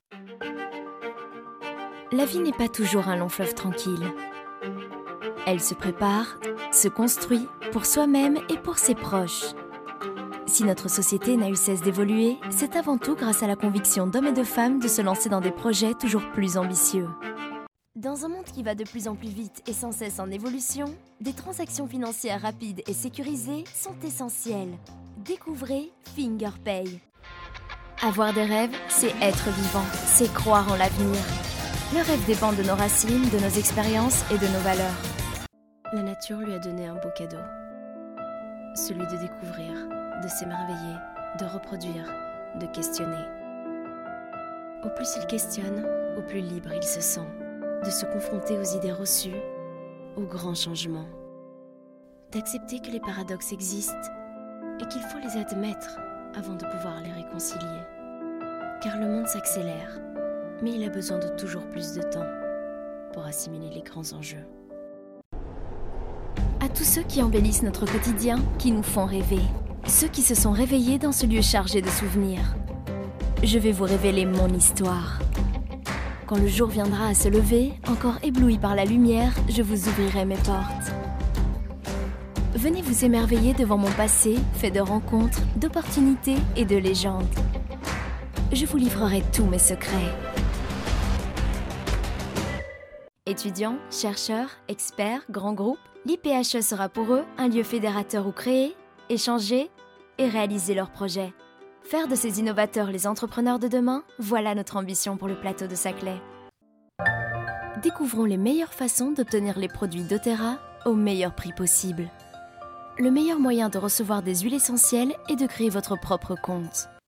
Junge, Natürlich, Verspielt, Urban, Cool
Unternehmensvideo